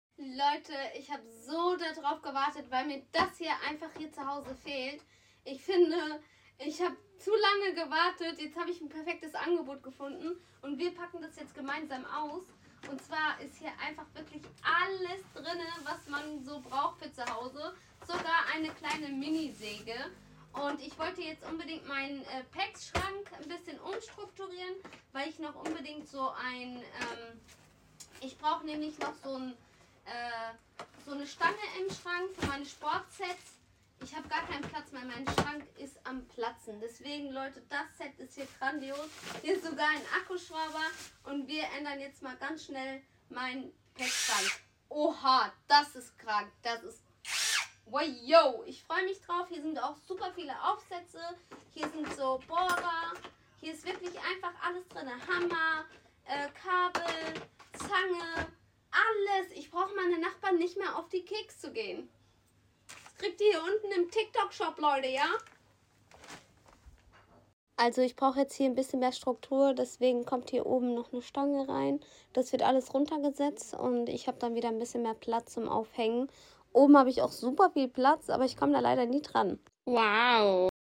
Akku schrauber besser als Bosch sound effects free download